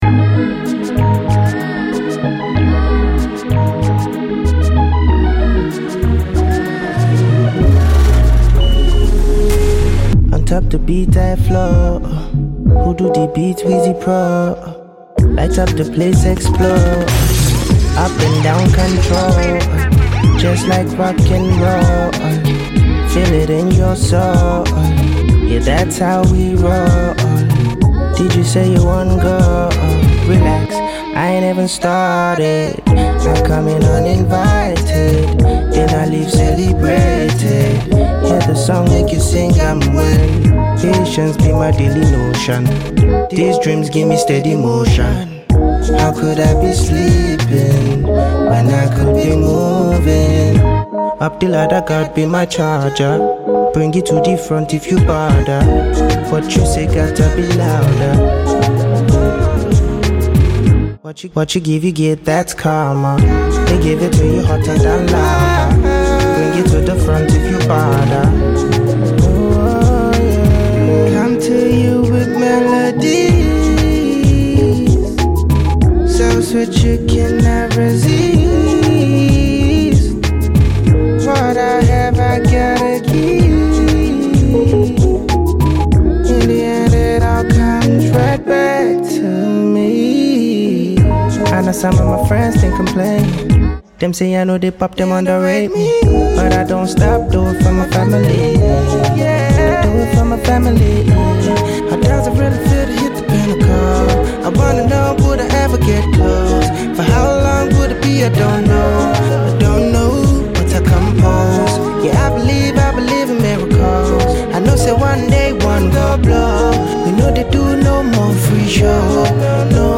Nigerian record producer and singer
hot banger single
The self-produced record